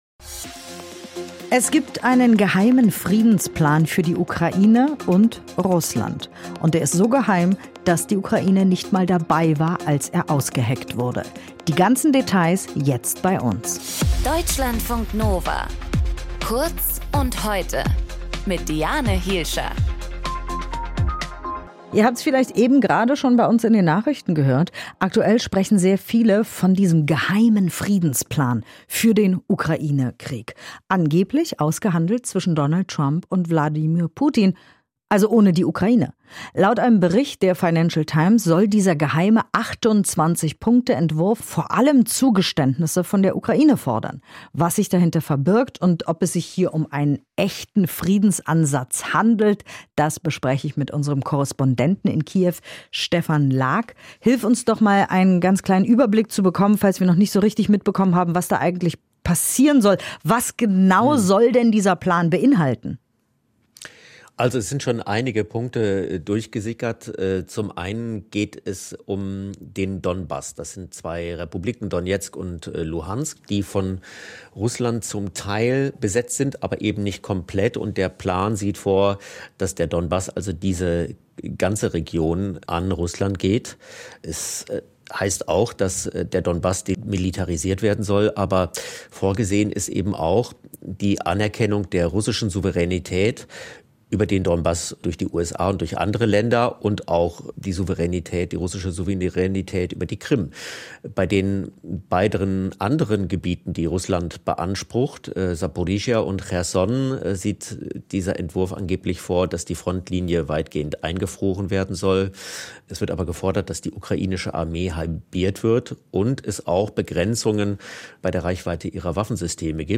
In dieser Folge mit: